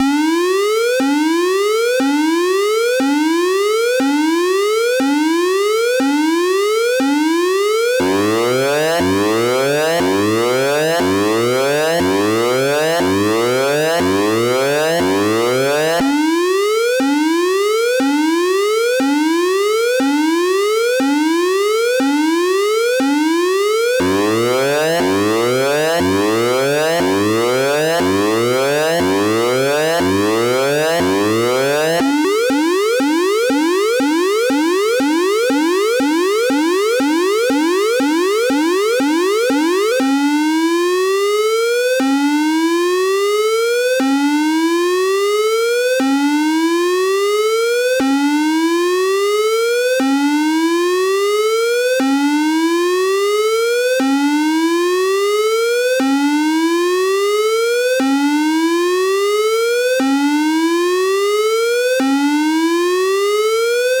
Alarm 1
Category: Sound FX   Right: Both Personal and Commercial